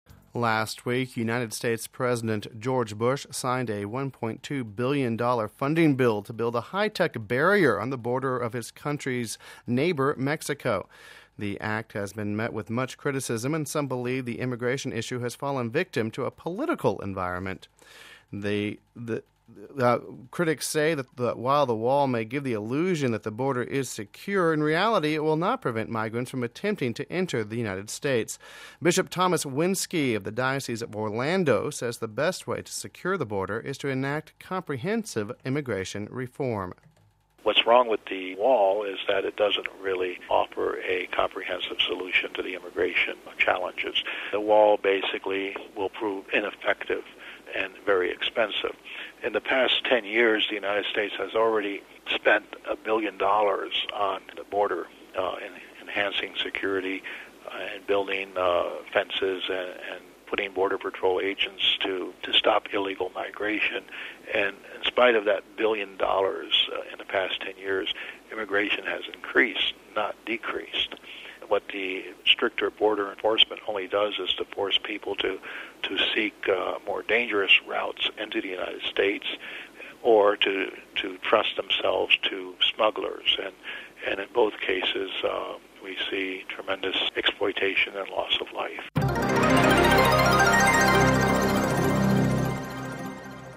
Home Archivio 2006-11-03 18:48:30 Wall Between Nations (3 Nov 06 - RV) The U.S. Bishops are opposing the government's plan to build a wall on the border with Mexico. We have this report...